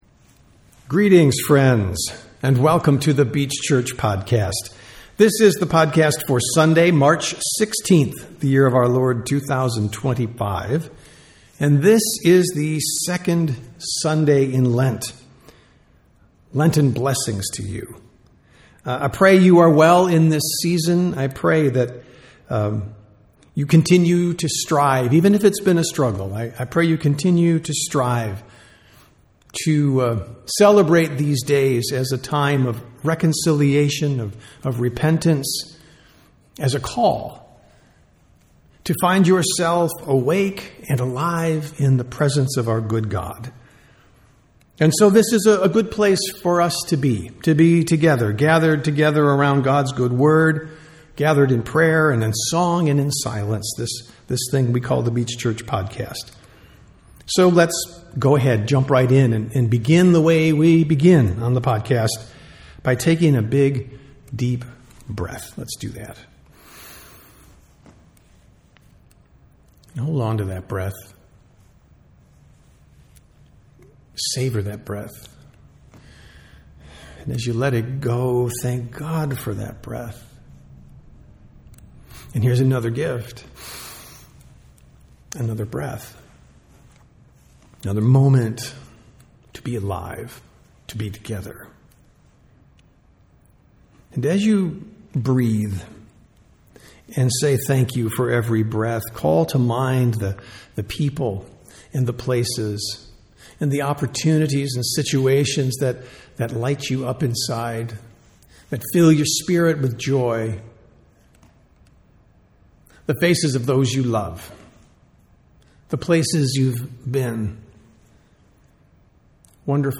Sunday Worship - March 16, 2025